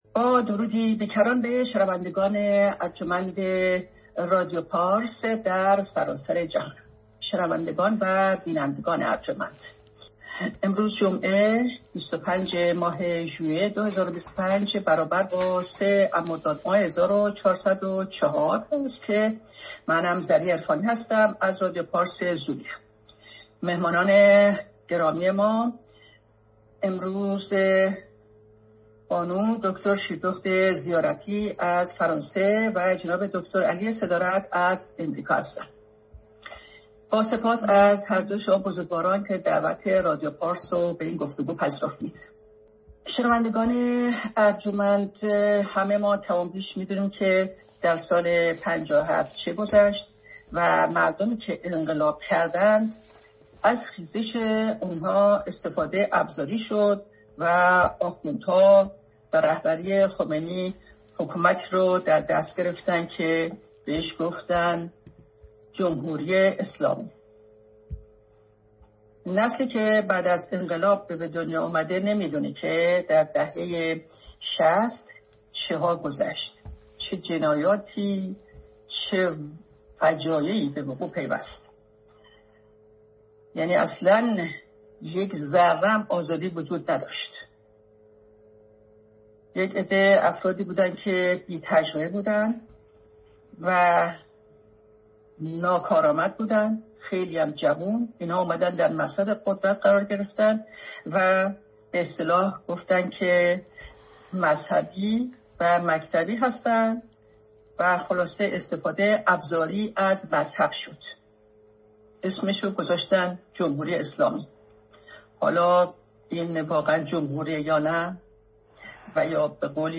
بحث آزاد